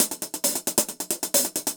Index of /musicradar/ultimate-hihat-samples/135bpm
UHH_AcoustiHatB_135-02.wav